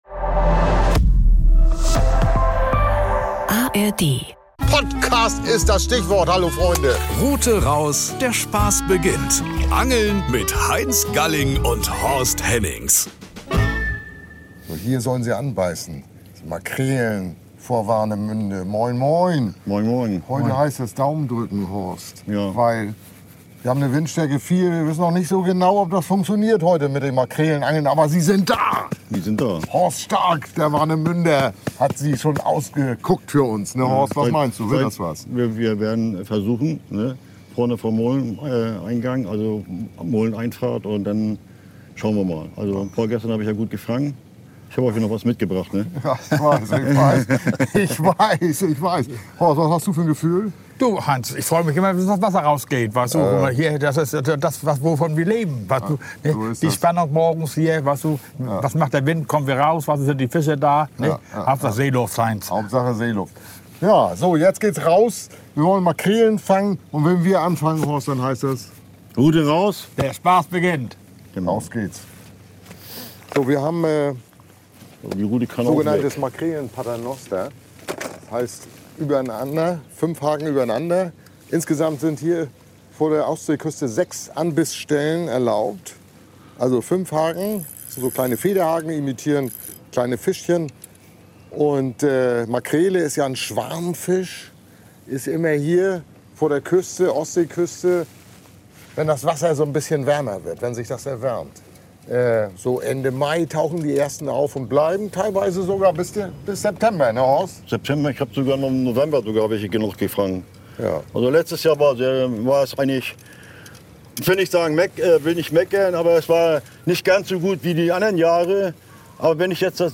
sind bei Windstärke 4 auf der Ostsee unterwegs und zeigen verschiedene Angelmethoden, mit denen der Schwarmfisch gefangen werden kann.